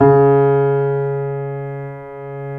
Index of /90_sSampleCDs/Roland - Rhythm Section/KEY_YC7 Piano pp/KEY_pp YC7 Mono